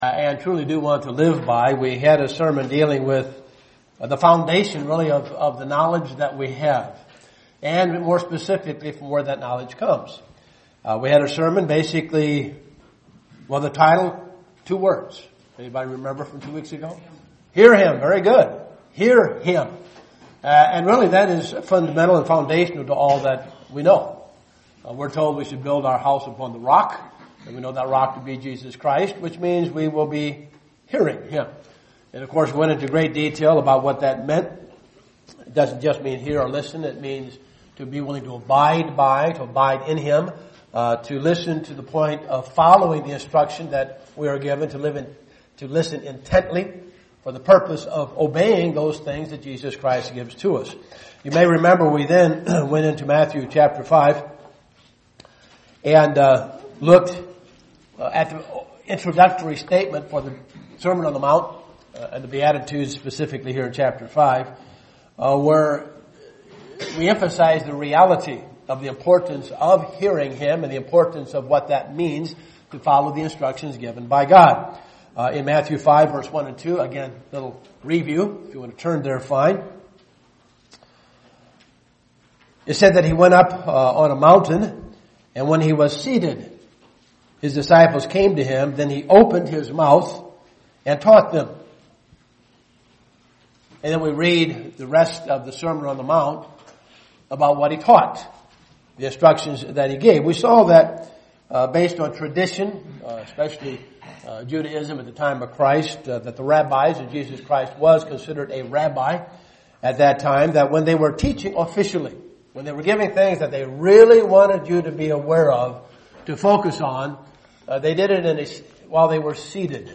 Listen to this sermon to find out what is meant by this.